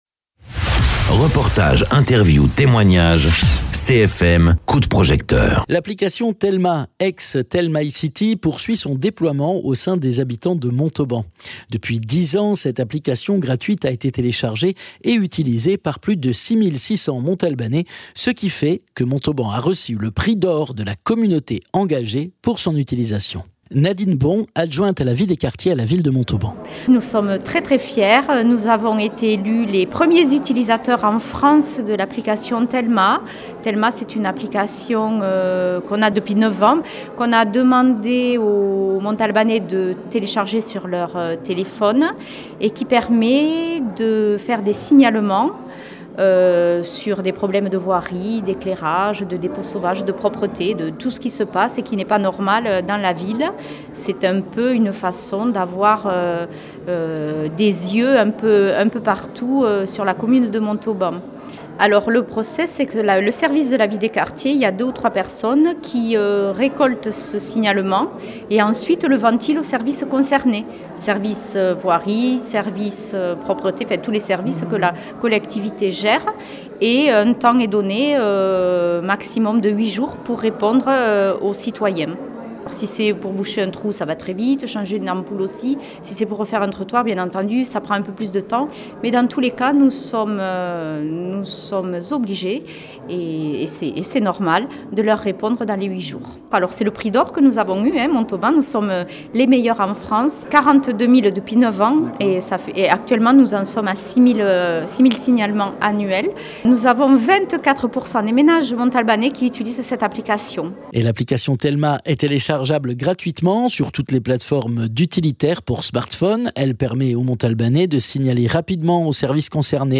Interviews
Invité(s) : Nadine Bon adjointe à la vie des quartiers à la ville de Montauban